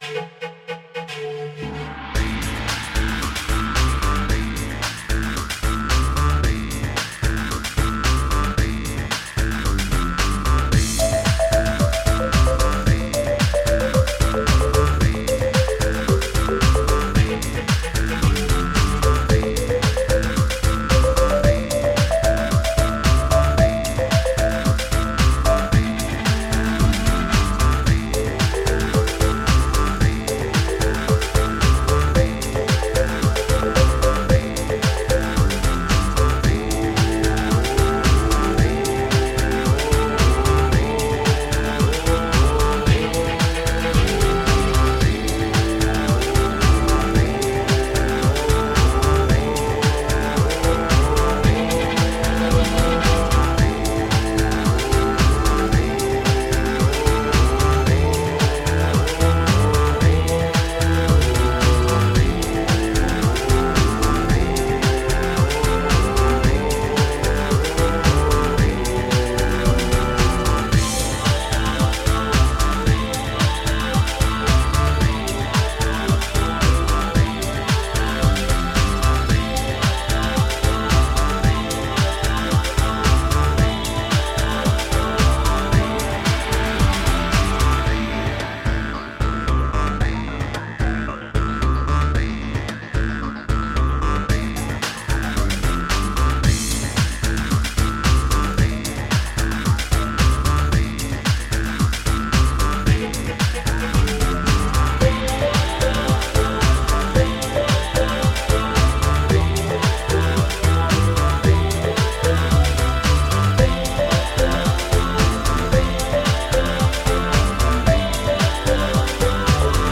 New age/world music for mankind from russia.
Tagged as: New Age, Inspirational, Chillout, Massage